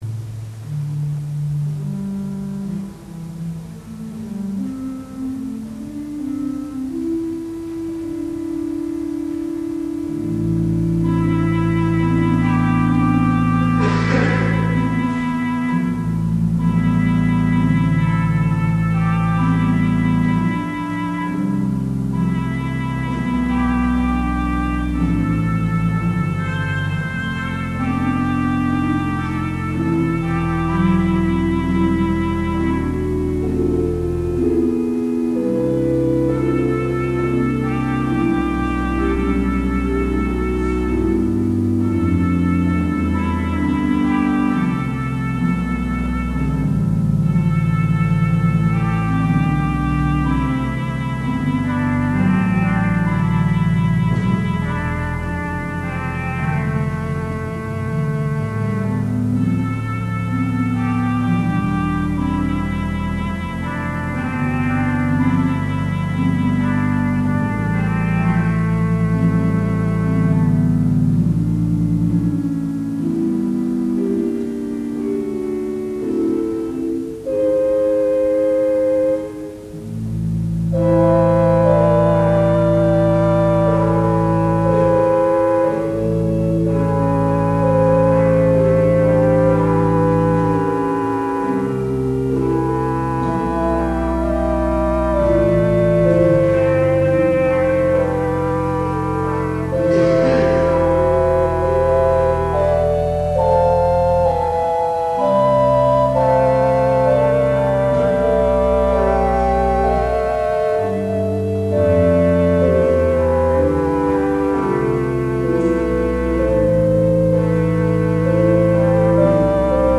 Orgelwerke